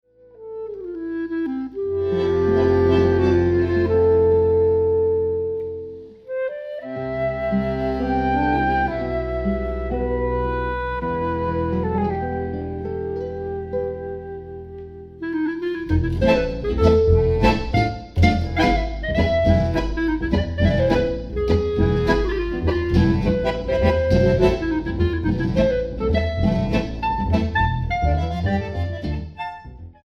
bandoneón